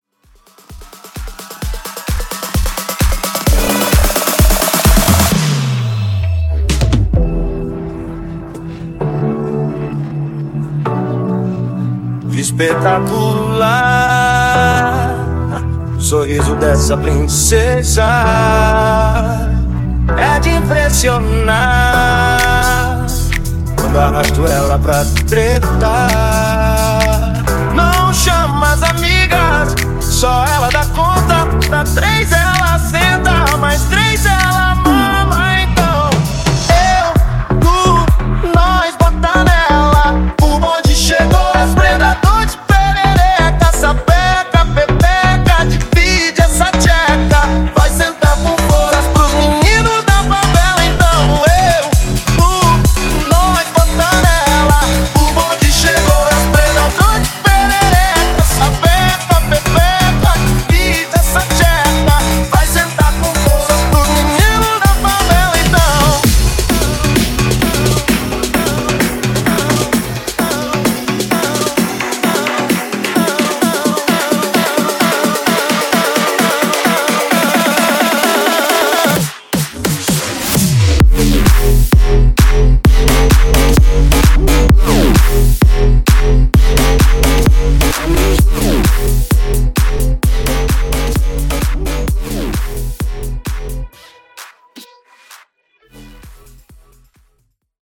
Bigroom Edit)Date Added